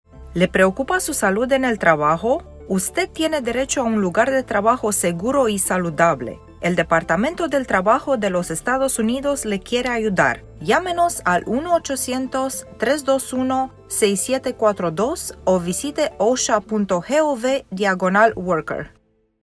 OSHA_SHW_RadioSpot2_Span.mp3